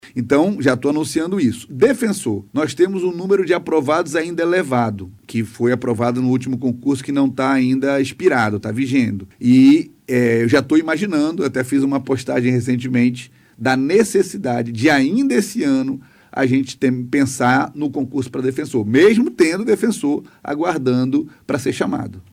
O anúncio foi feito durante entrevista à BandNews Difusora nesta segunda-feira (22) ao falar sobre os projetos de expansão da Defensoria.